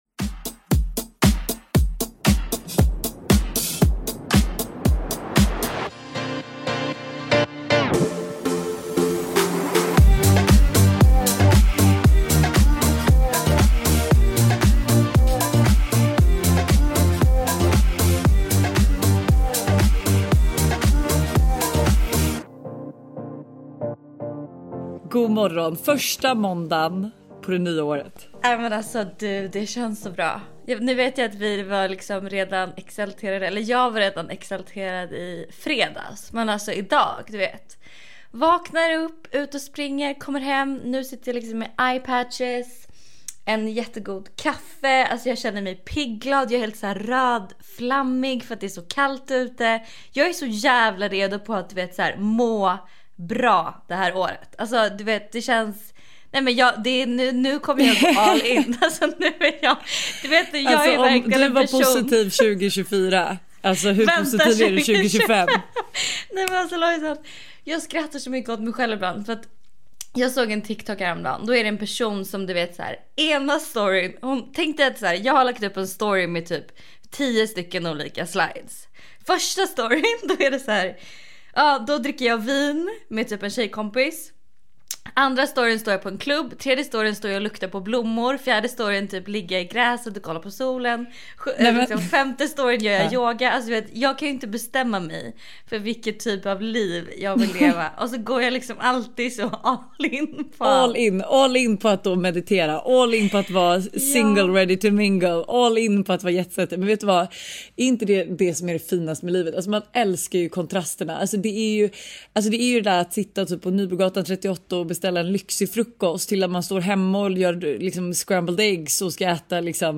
… continue reading 511 つのエピソード # Samtal # Bauer Media # Samhällen